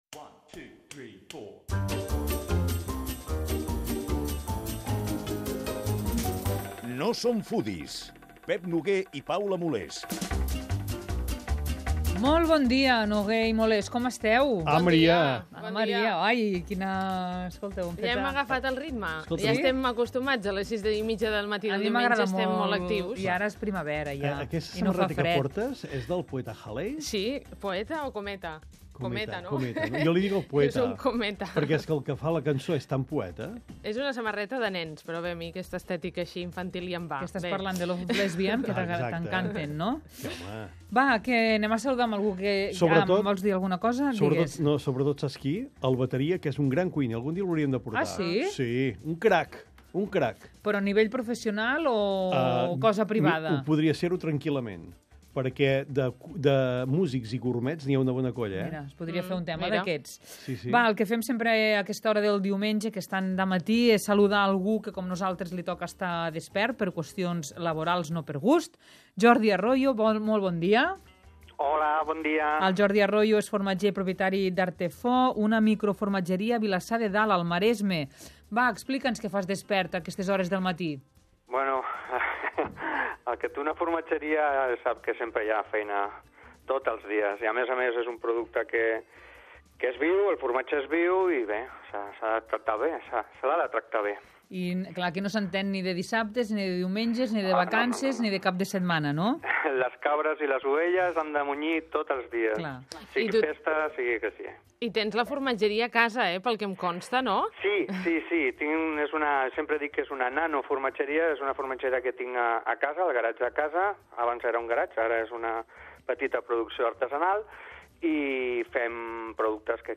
Torna l’etern debat sobre què és i què no és una paella, sobre què és autèntic i tradicional i què no ho és. Parlem amb un arrosser del País Valencià i amb un altre de català.